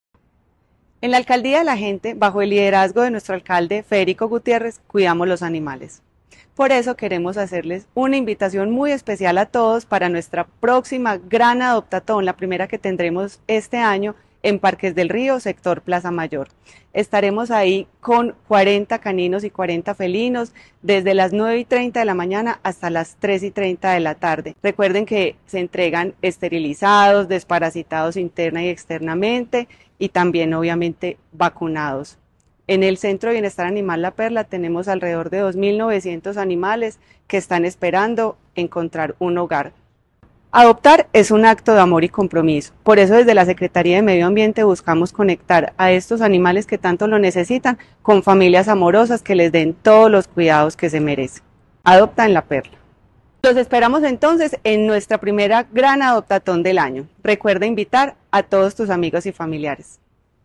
Palabras de Elizabeth Coral Duque, secretaria (e) de Medio Ambiente La Alcaldía de Medellín, a través de la Secretaría de Medio Ambiente, realizará la primera ‘adoptatón’ de este 2025.